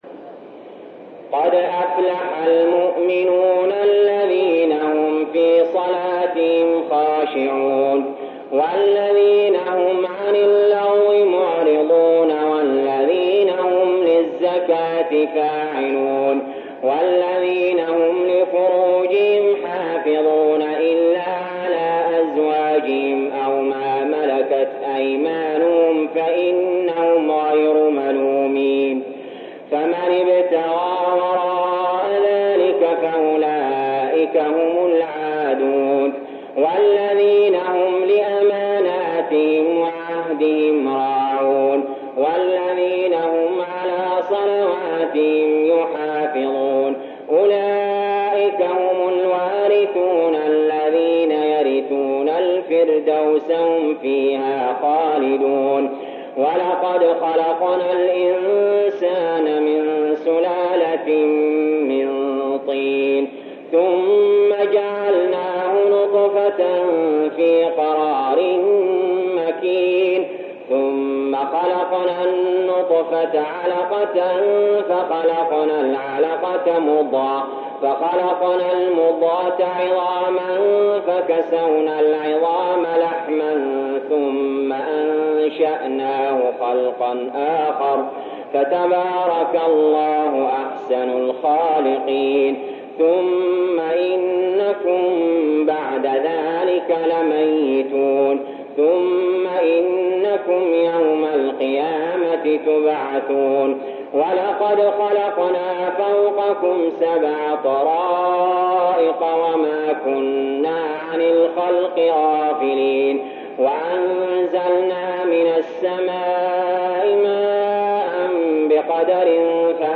المكان: المسجد الحرام الشيخ: علي جابر رحمه الله علي جابر رحمه الله المؤمنون